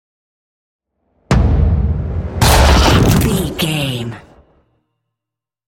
Double hit with whoosh shot explosion
Sound Effects
heavy
intense
aggressive
hits